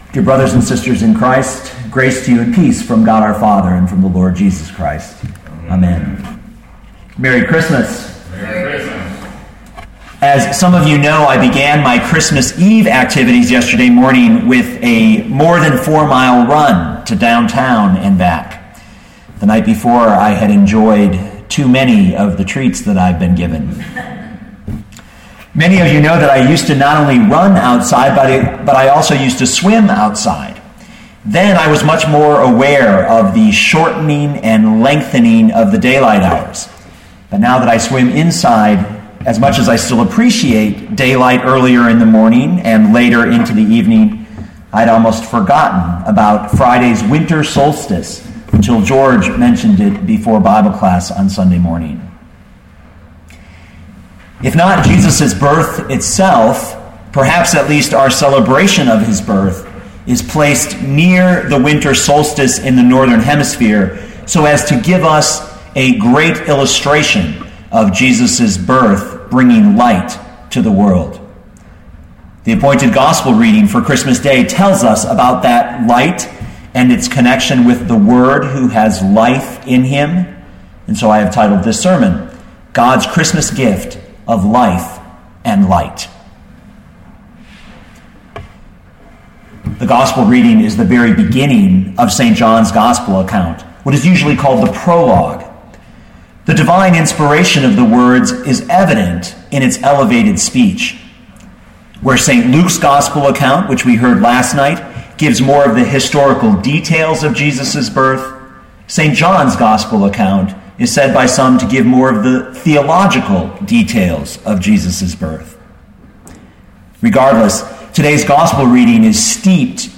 gods-christmas-gift-of-life-and-light.mp3